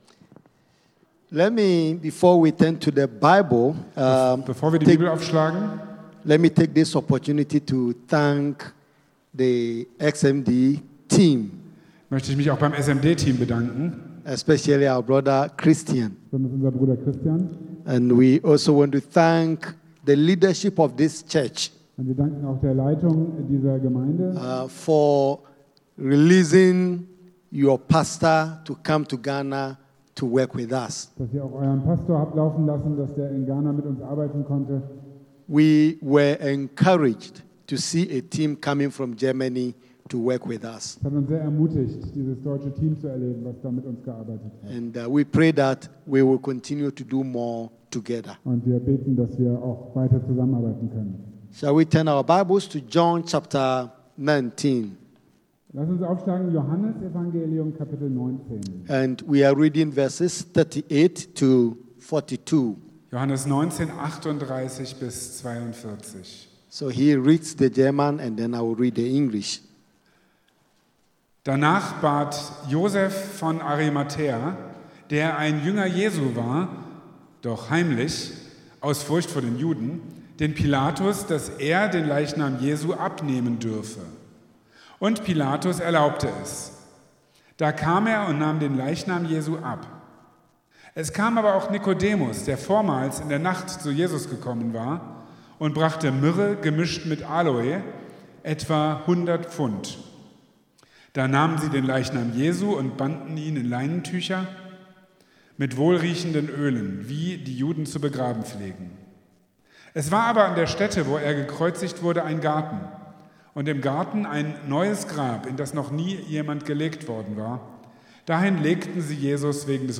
(Englisch mit deutscher Übersetzung)